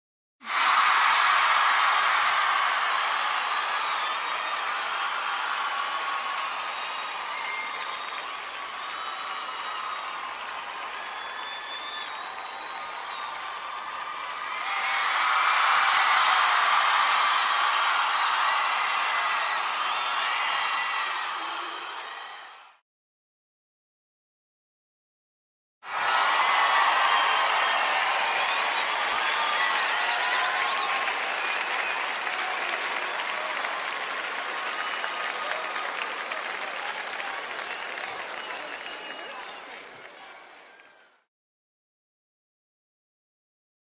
Звук толпы в помещении большой концертной арены
Толпа, публика